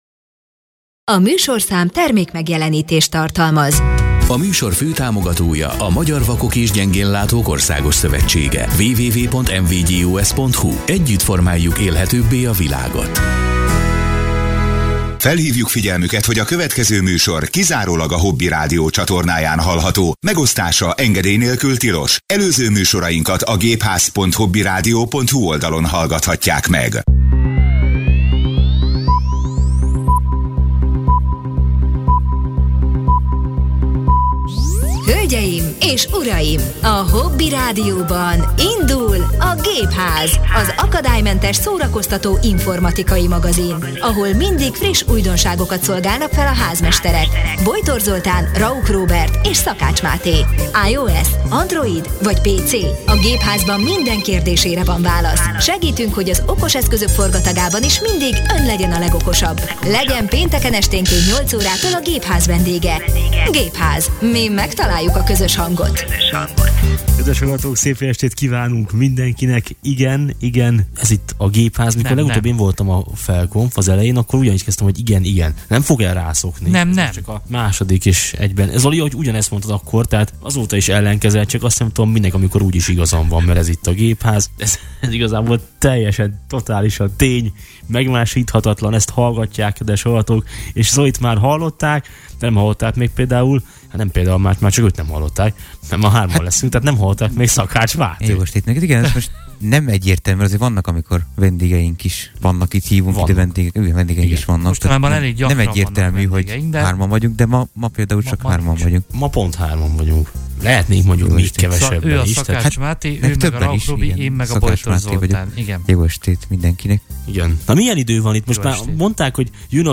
Műsorunk első néhány percében hallgatóink leveleiből szemezgettünk, majd beszélgettünk a héten debütált új iOS rendszerről, amely az Apple új nevezékrendszere szerint a 26-os verziószámot kapta. Némi hangos bemutatót is prezentáltunk a megváltozott Telefon alkalmazásról és egyéb újdonságokról, mindezek után pedig előkaptunk egy szemüveget, amelyet egyik kedves hallgatónktól kaptunk egy rövid tesztelés erejéig.